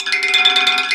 METAL HIT 8.wav